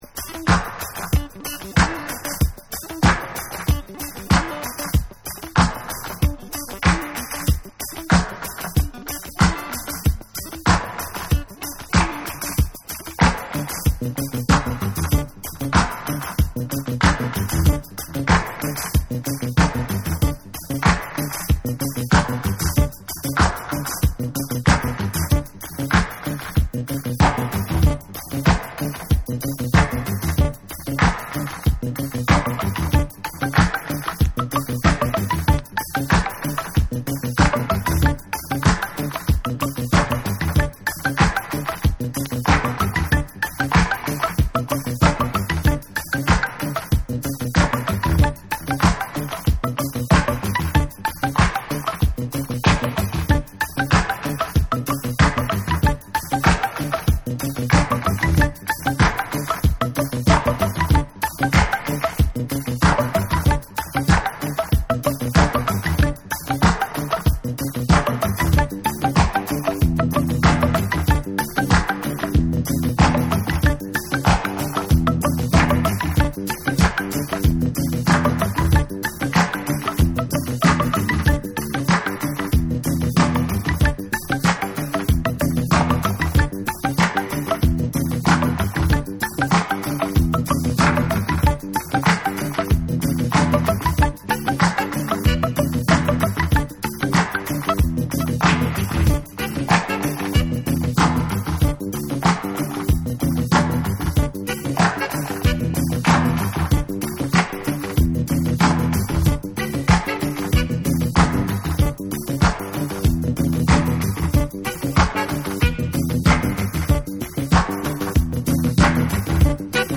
サイケデリックな怪しいシンセが浮遊するニューウエーヴ・ディスコ
DANCE CLASSICS / DISCO